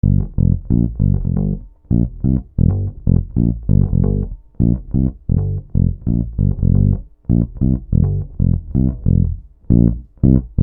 Bass 05.wav